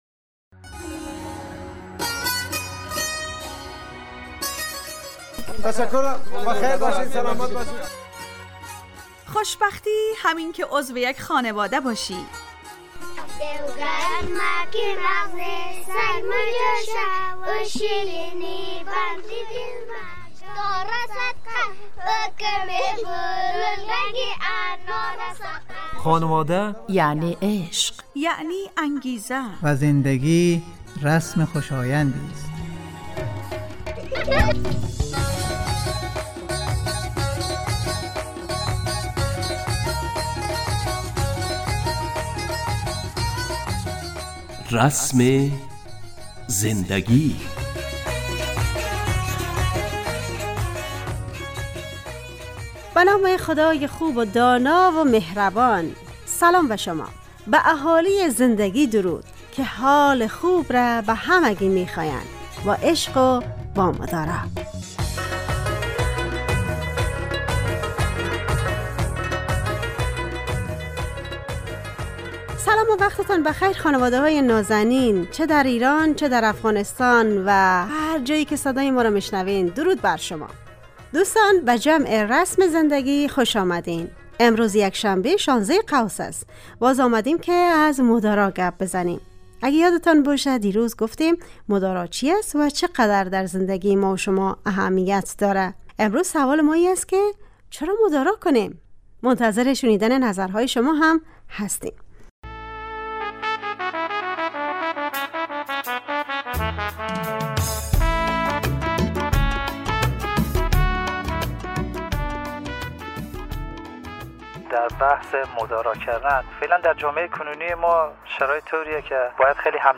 برنامه خانواده رادیو دری